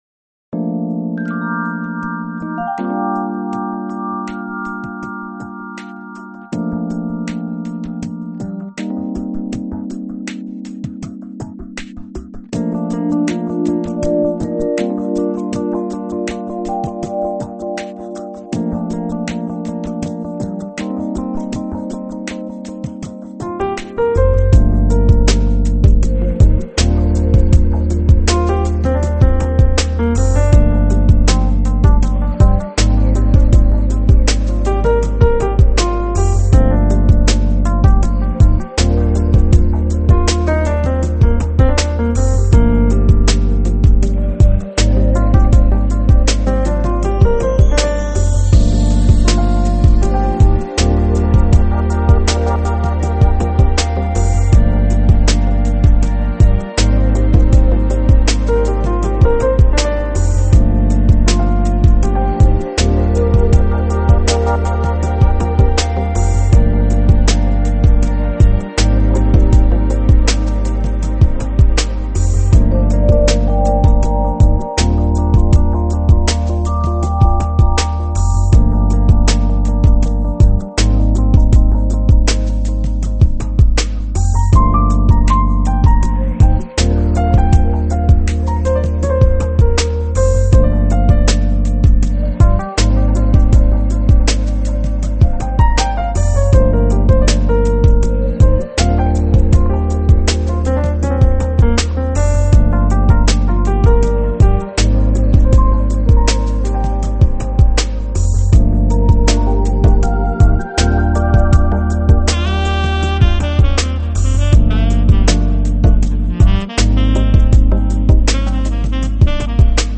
Lounge, Chill Out, Smooth Jazz, Easy Listening